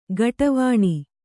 ♪ gaṭavāṇi